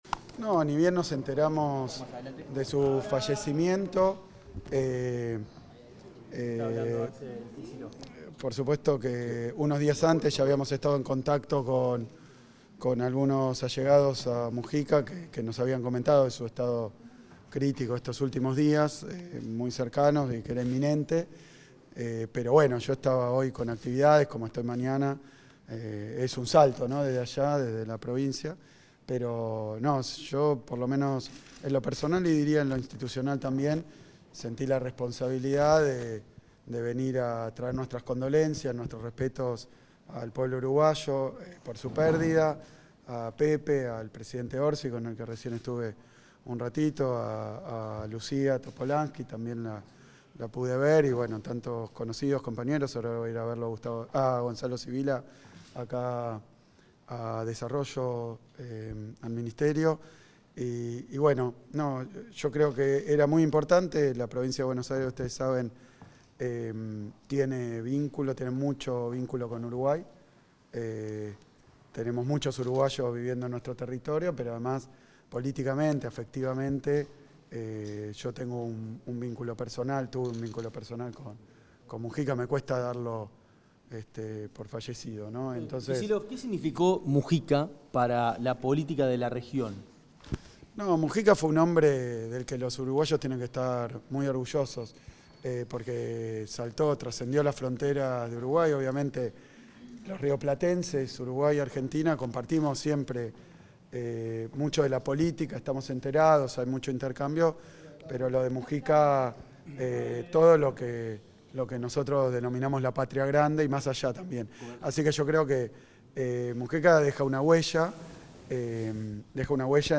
Declaraciones del gobernador de la provincia de Buenos Aires, Axel Kicillof
Declaraciones del gobernador de la provincia de Buenos Aires, Axel Kicillof 14/05/2025 Compartir Facebook X Copiar enlace WhatsApp LinkedIn En oportunidad del velatorio del expresidente José Mujica, el gobernador de la provincia de Buenos Aires, República Argentina, Axel Kicillof, realizó declaraciones a la prensa.